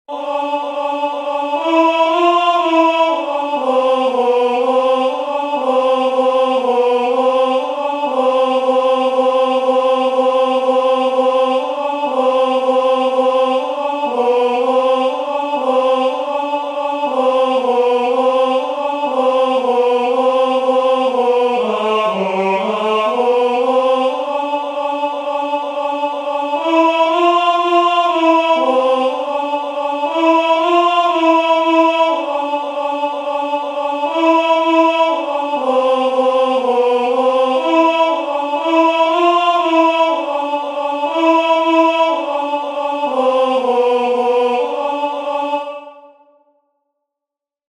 "Cum vos oderint," the second responsory verse from the second nocturn of Matins, Common of Apostles